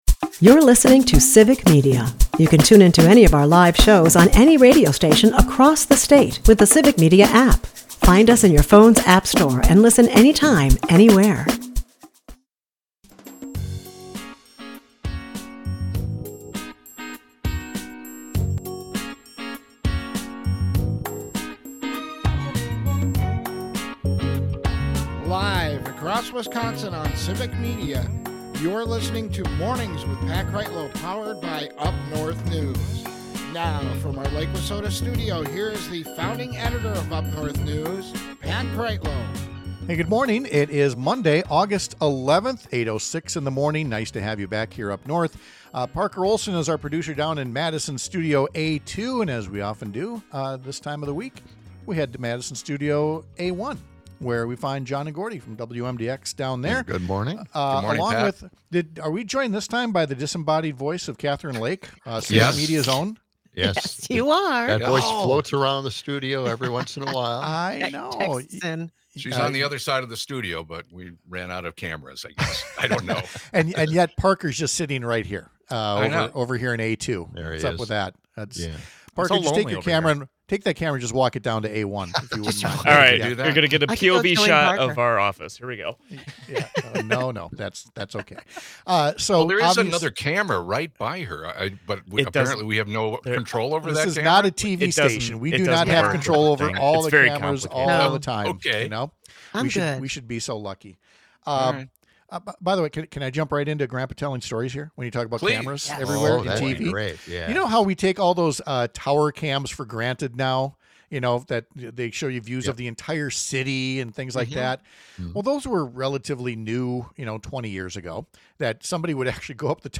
For state lawmakers, that’s the role of the National Conference of State Legislators. We’ll talk to Rep. Jodi Emerson (D-Eau Claire) about this year’s convention discussions — and the difference between a group like NCSL vs. similar groups focused on partisanship and division.